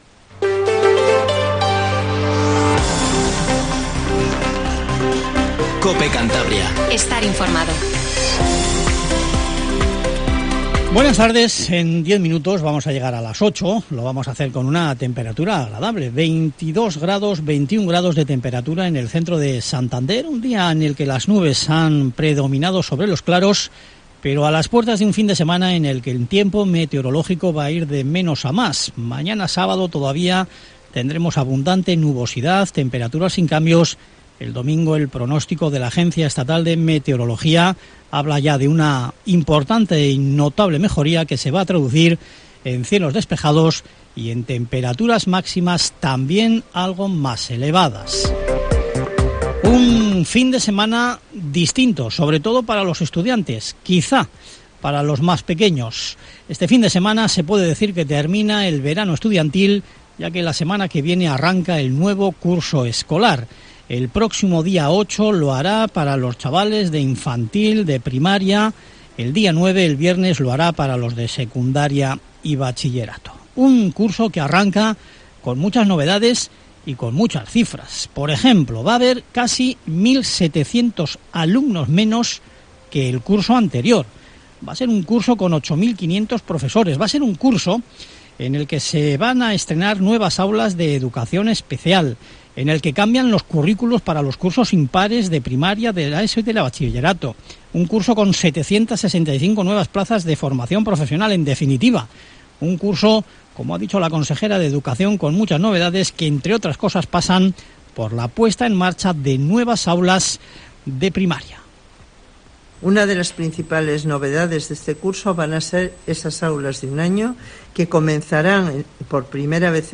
Informativo Tarde COPE CANTABRIA